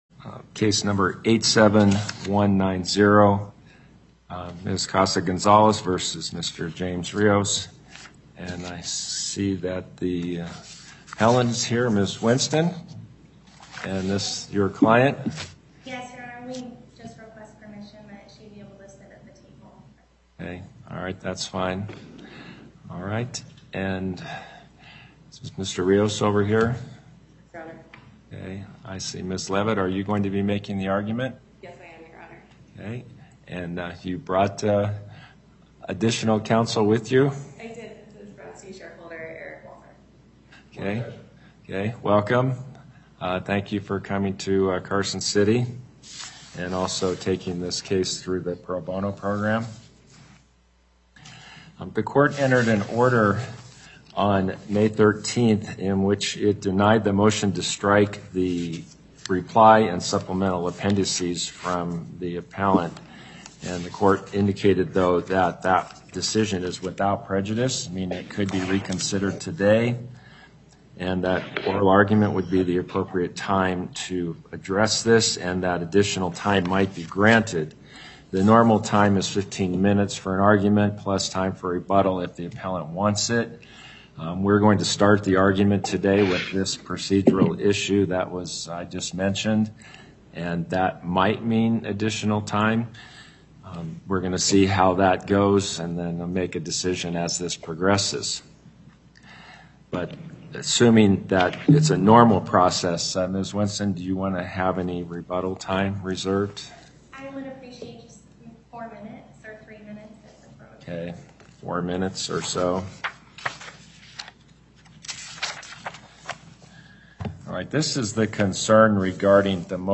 Before the Court of Appeals, Chief Judge Gibbons presiding
as counsel for Appellant
as counsel for Respondent